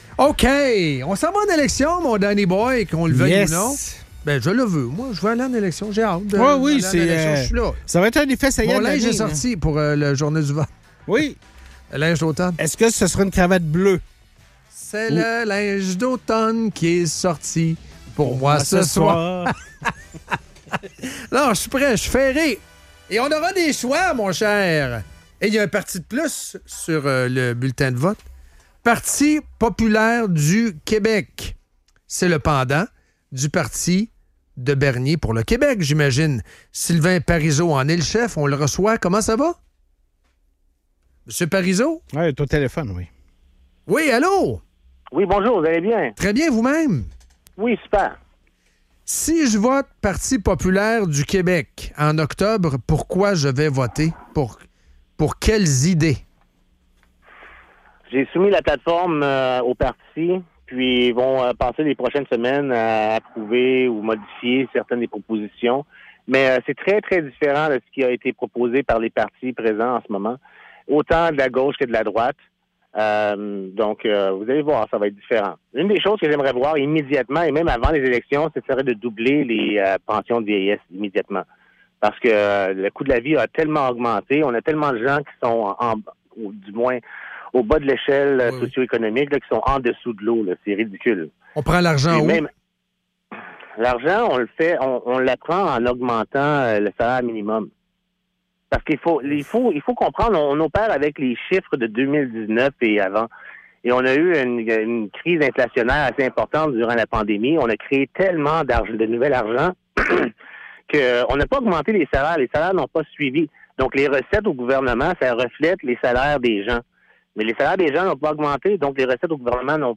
Entrevue SPATIALE avec le chef du Parti Populaire du Québec!